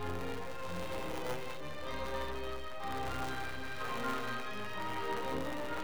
Its actually training stuff for choral singing where most of the content is in the left channel and the bass part that I have to learn is in the right channel so it is easy to hear and see the difference between the two channels.
I have attached a couple of files of the distortion. I know this sounds a bit like something is being overdriven but I have tried turning everything down to the point that Audacity no longer accepts it (get an “Error while opening sound device” message).
Its difficult to describe – like a Honky Tonk splurge sound.
The sound clips have very strange distortion.